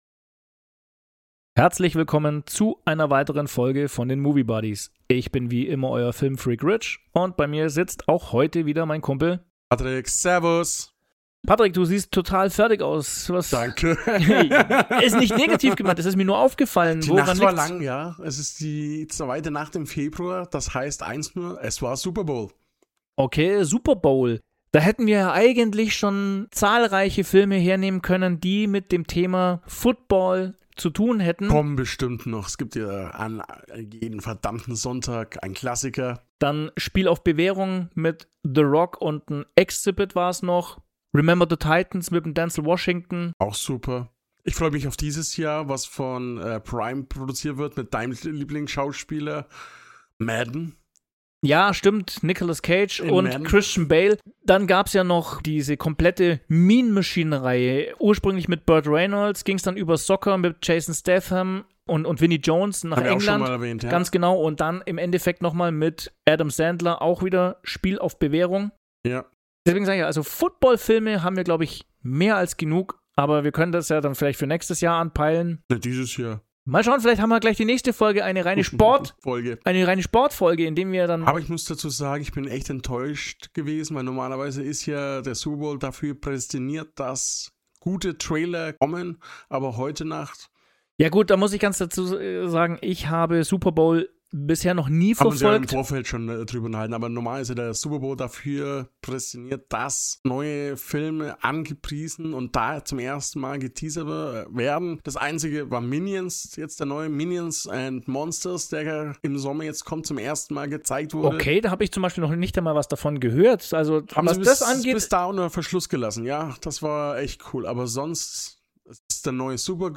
Erlebt eine lustige Konversation über den Film, seine Darsteller und die Wirkung einiger ausgewählter Szenen.